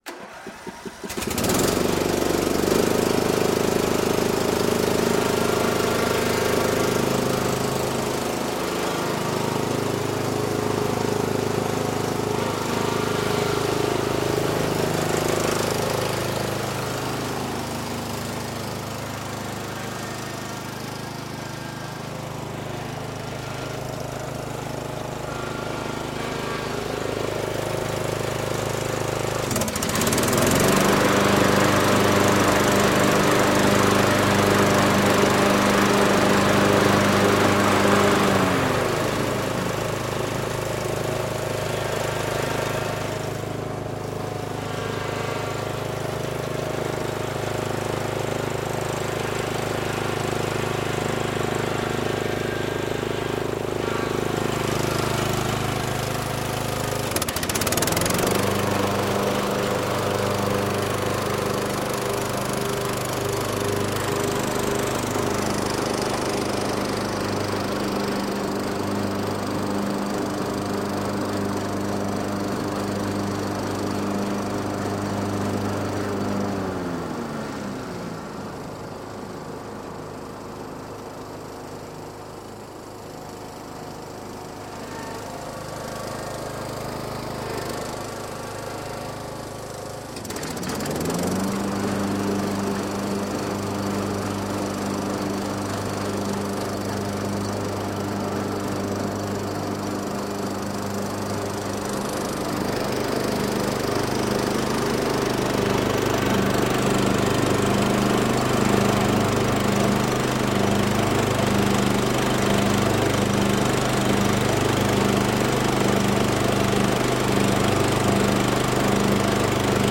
Бензиновая газонокосилка Hayter Harrier легко запускается и отлично косит